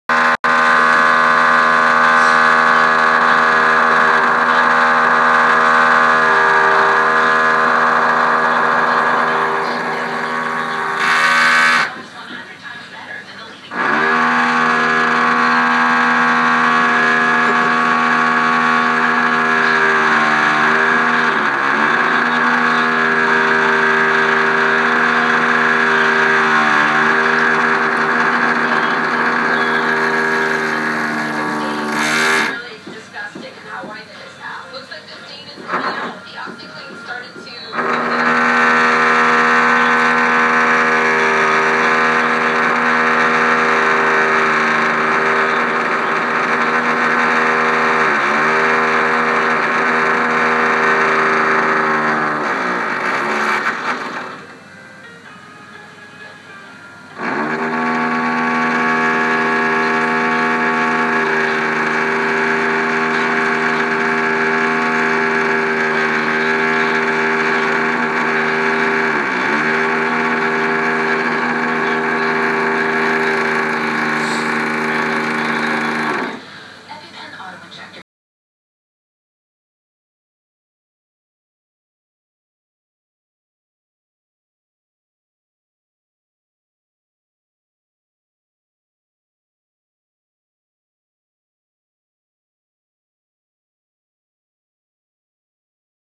The department store below my New York apartment has been under construction for a year and a half. Seven days a week, from 7:00 a.m. until 6:00 p.m., they jack-hammer the foundation until stuff falls off the shelves, rivet things into the ceiling under my floor, bang, power-sand, and run their what-have-yous.
How loud does it get? Give a listen.
barneys-noise1.m4a